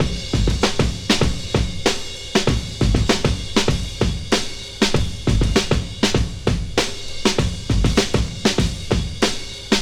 • 98 Bpm Fresh Breakbeat A# Key.wav
Free drum loop sample - kick tuned to the A# note. Loudest frequency: 1412Hz
98-bpm-fresh-breakbeat-a-sharp-key-E7Z.wav